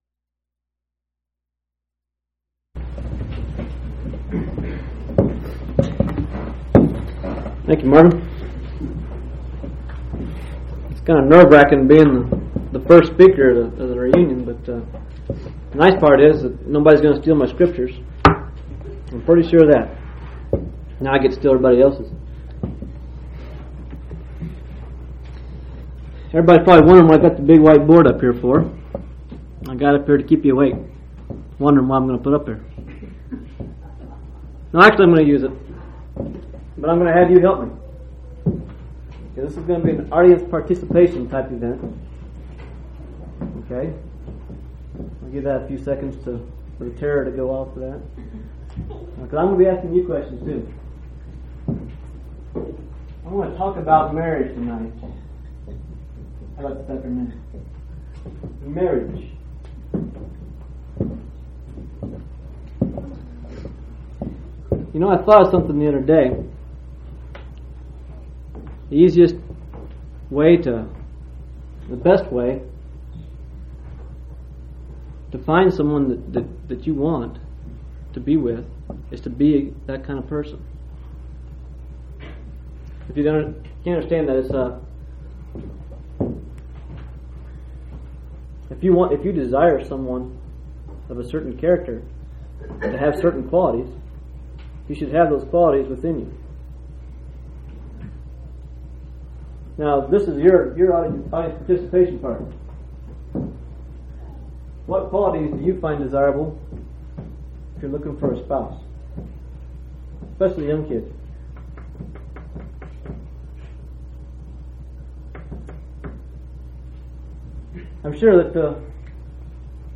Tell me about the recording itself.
Colorado Reunion Event: Colorado Reunion